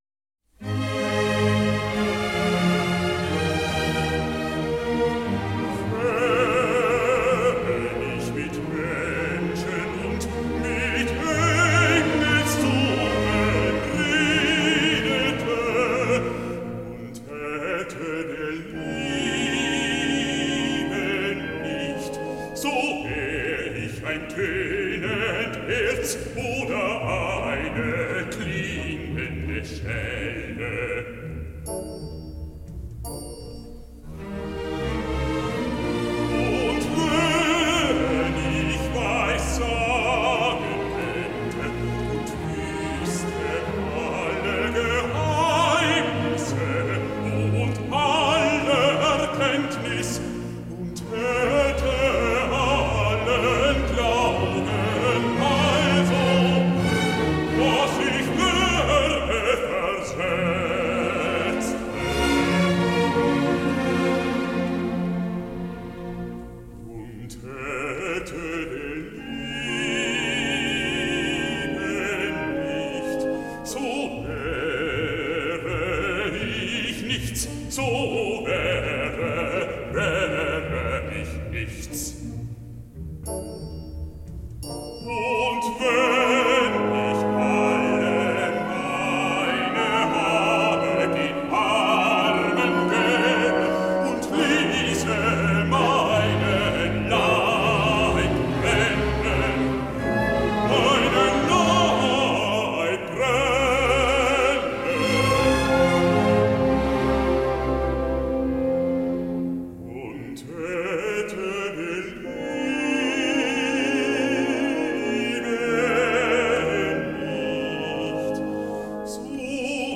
Im Satz für Bariton und Orchester